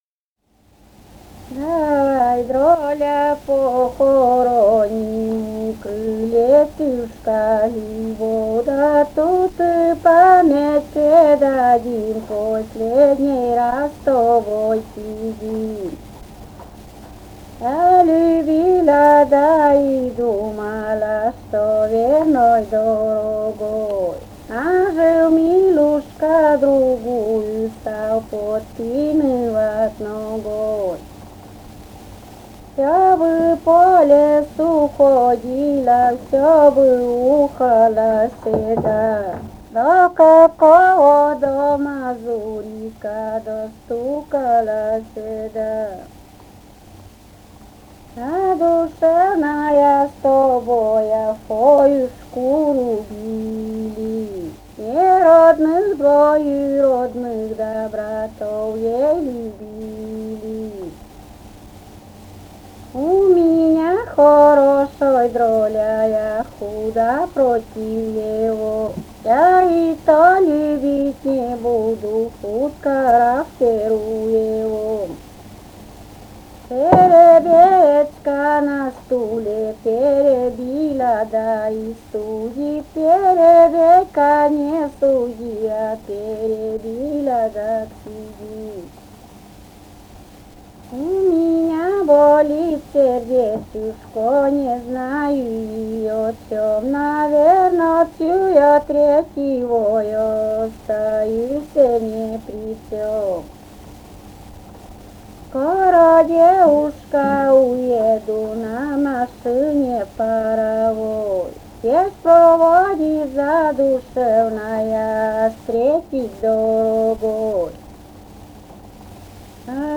«Давай, дроля» (частушки).